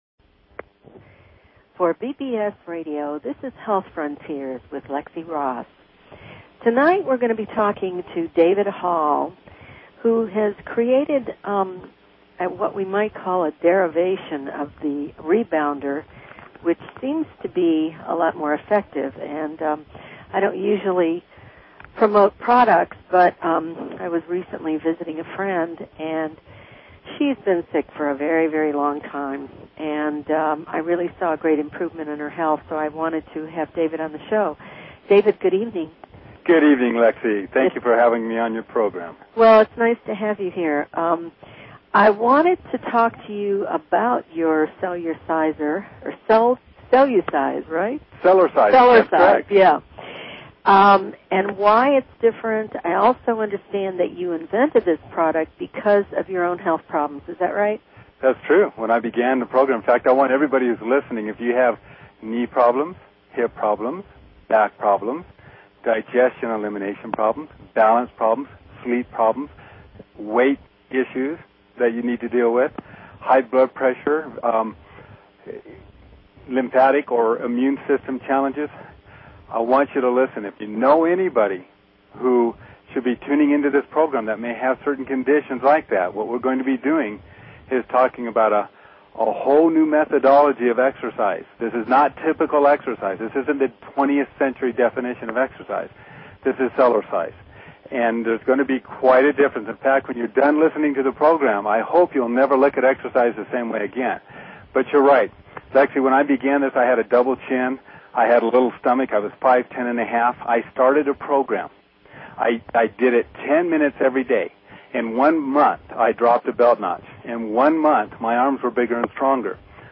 Talk Show Episode, Audio Podcast, Health_Frontiers and Courtesy of BBS Radio on , show guests , about , categorized as